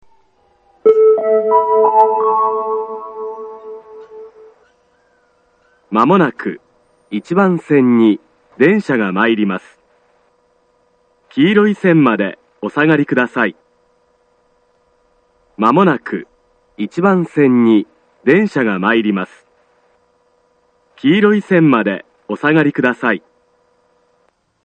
１番線接近放送
１番線発車メロディー 曲は「Cielo Estrellado」です。